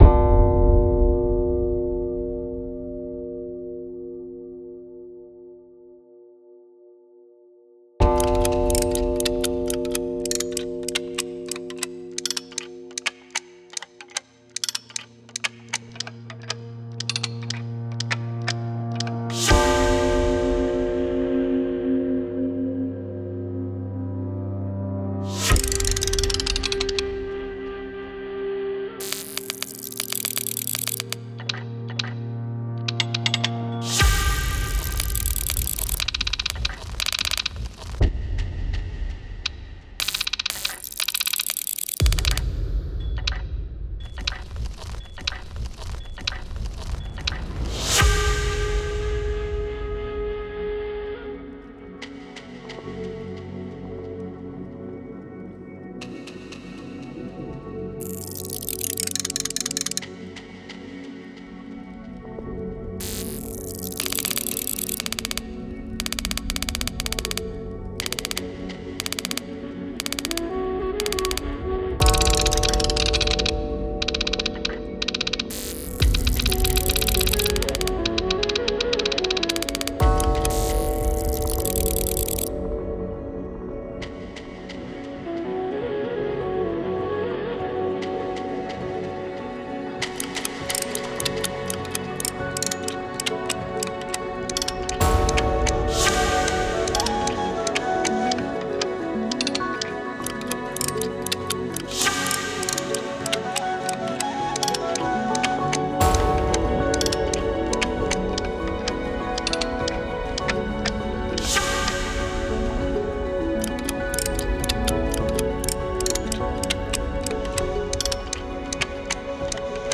Звуковые инсталляции, наполняющие территорию Музея-заповедника «Казанский Кремль», окликают современность из прошлого, вторят пестрому многоголосию истории.
История Спасской башни Кремля оживает в мгновенных картинах, запечатлевших ее меняющийся в разные эпохи облик: от начала строительства в середине XVI века до сегодняшнего дня. Главным героем сюжетов, метафорическим символом времени, стали башенные часы: изначально простые механические, после – электрические с автоматическим боем.